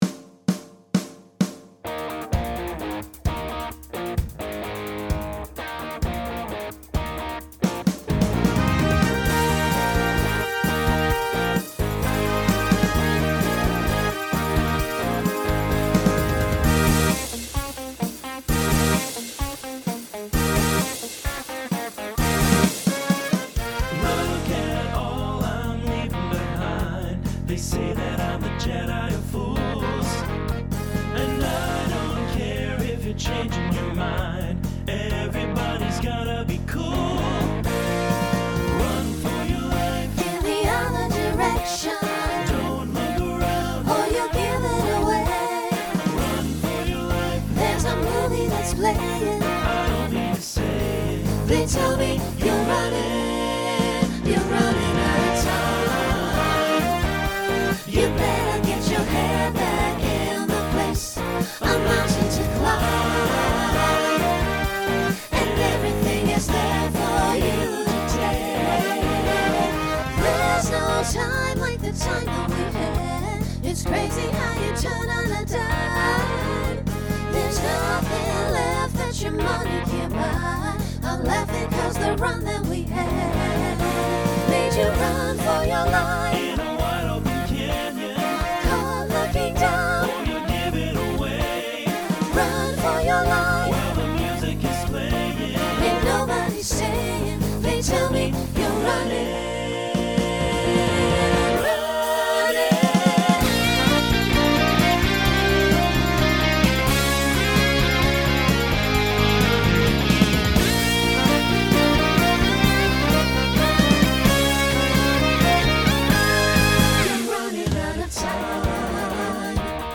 Genre Rock Instrumental combo
Transition Voicing SATB